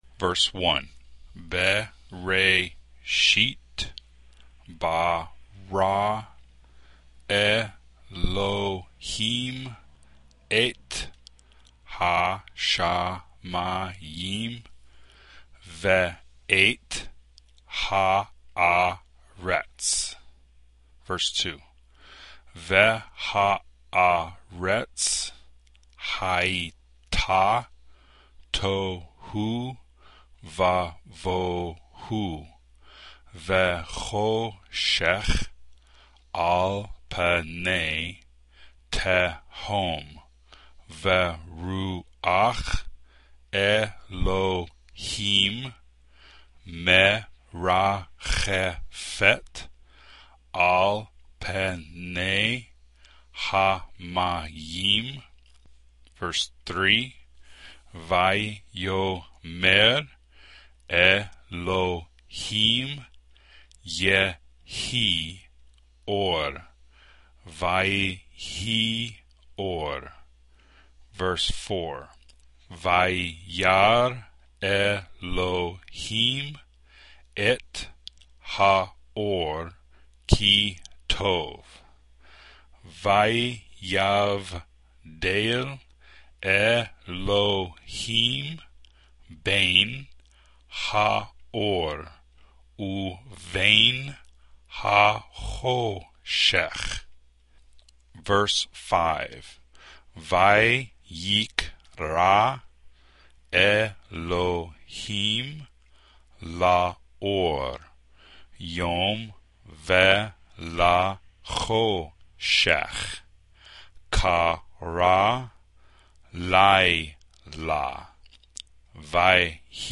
The audio for this passage will first be read very slowly then followed by a reading of a normal pace.